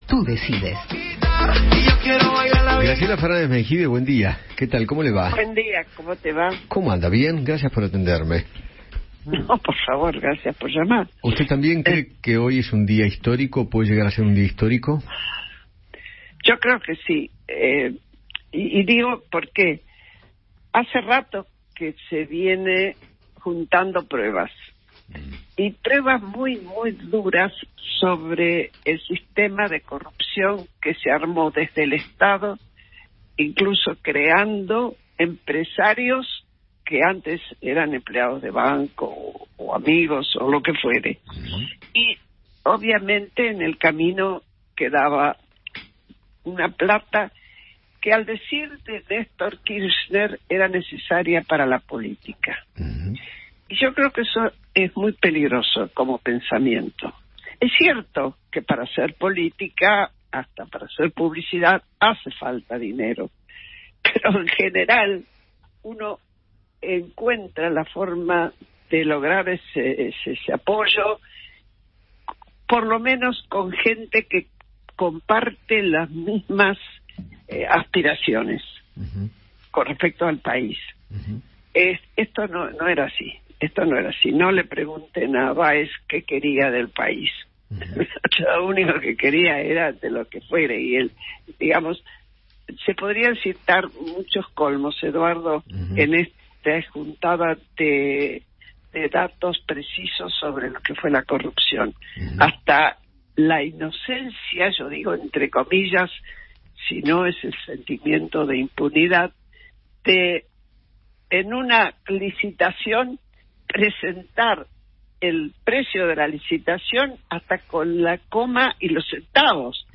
Graciela Fernández Meijide, integrante de la CONADEP, charló con Eduardo Feinmann sobre la última jornada de alegatos de la Causa Vialidad y destacó el accionar del fiscal Diego Luciani.